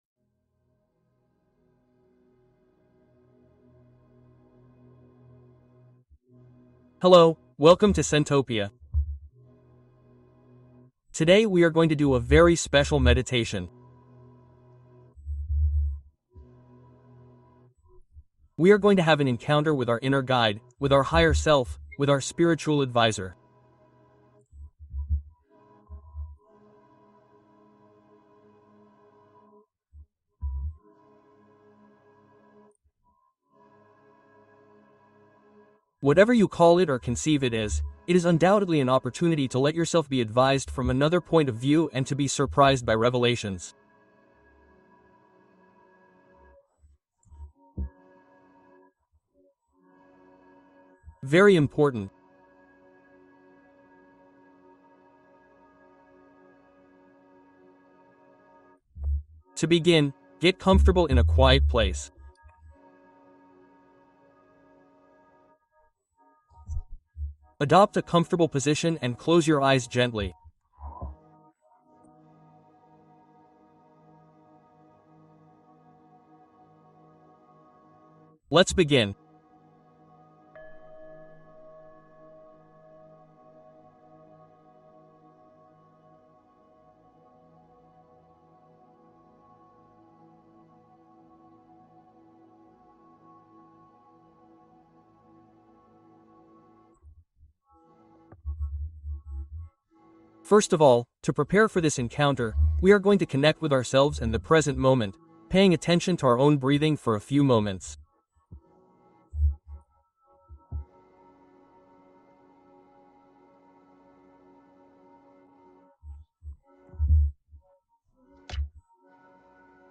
Meditación para contactar con tu guía interior o yo superior